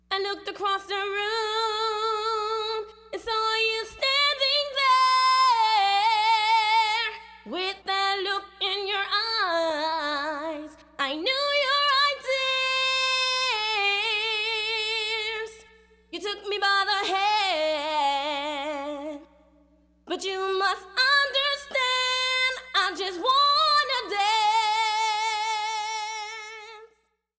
two more early 90's N.Y. house tracks.
House